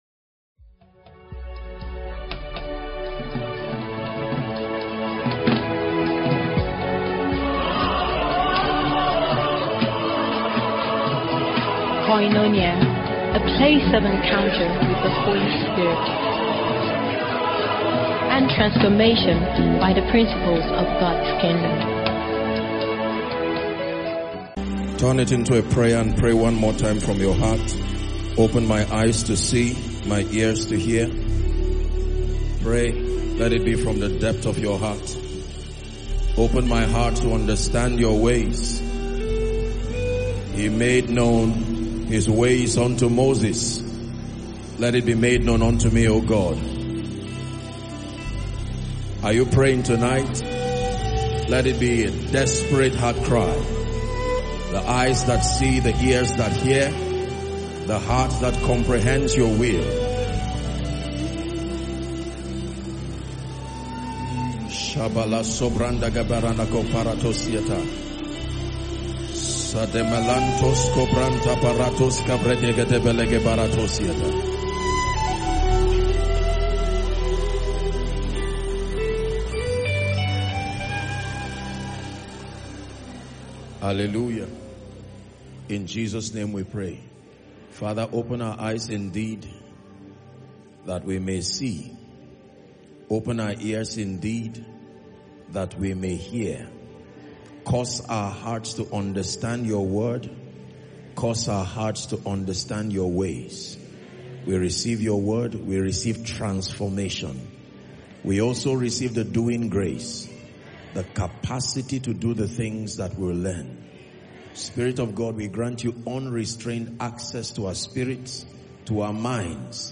The sermon challenges long-held misconceptions about poverty and reveals spiritual principles that govern economic emancipation in the life of a believer.